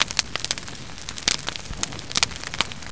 feuer.wav